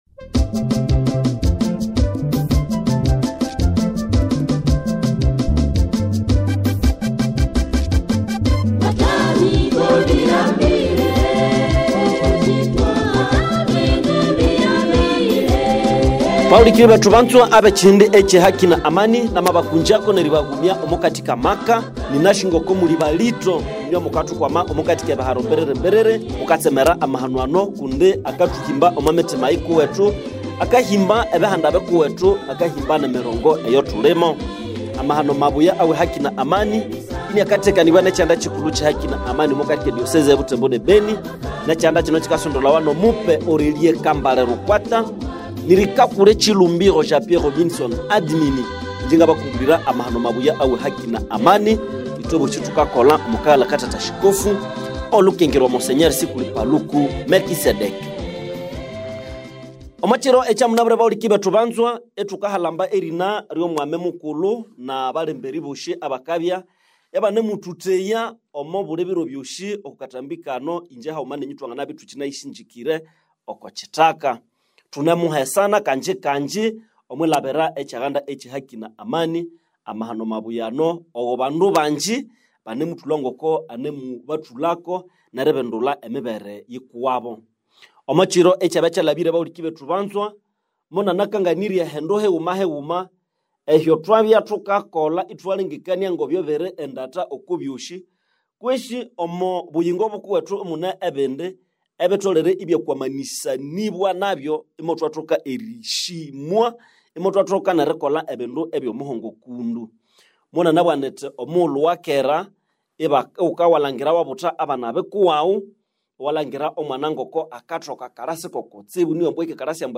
Émission Radio Écoutez l'émission ci-dessous